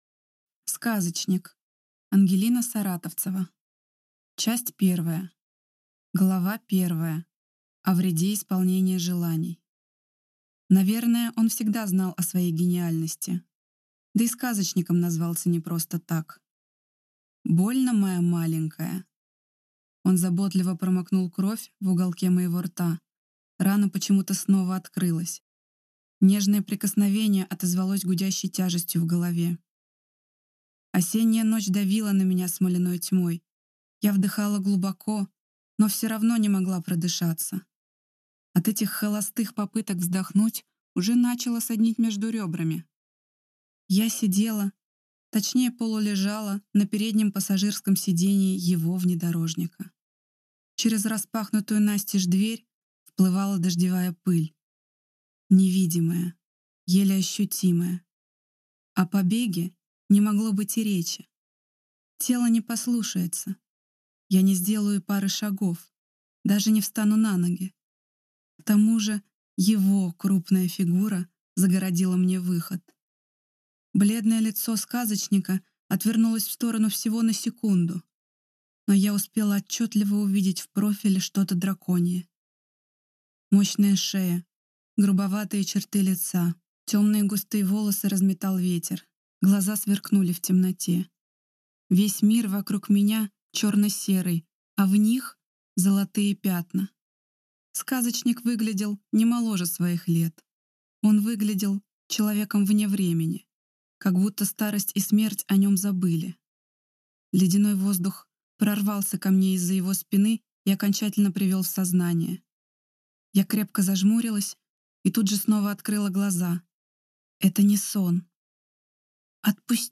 Аудиокнига Сказочник | Библиотека аудиокниг